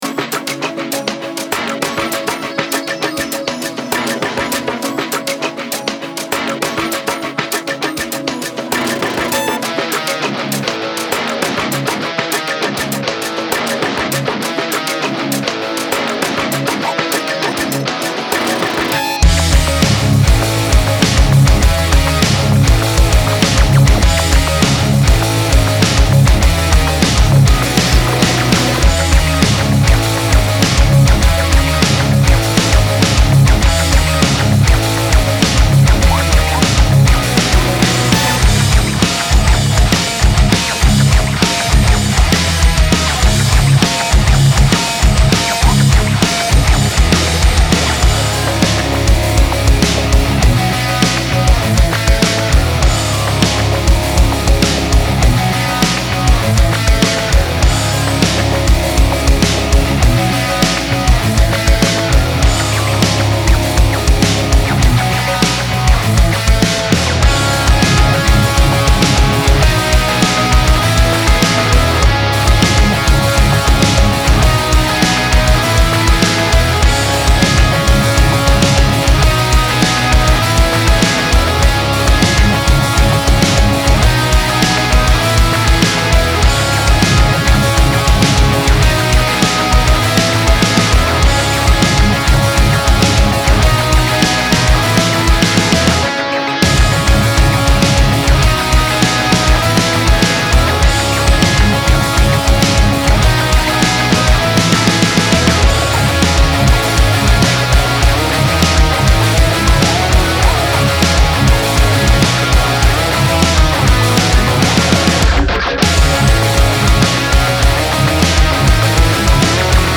ジャンル ヘヴィメタル